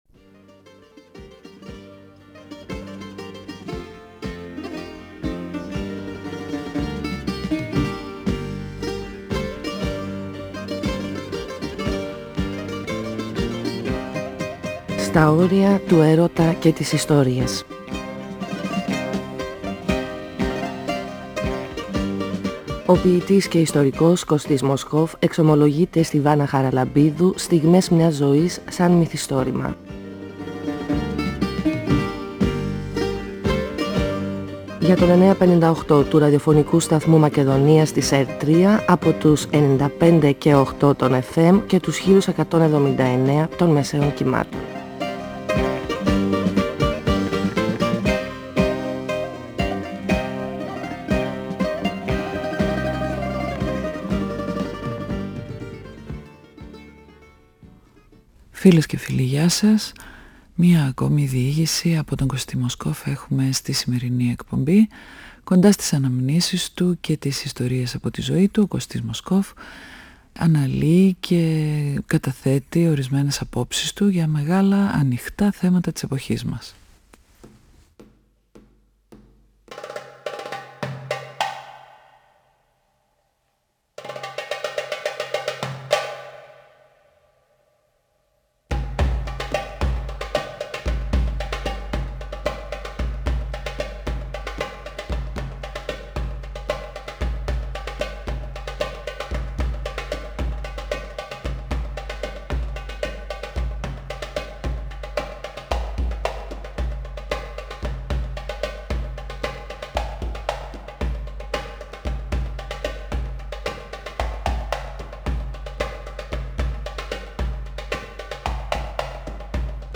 Η συνομιλία–συνέντευξη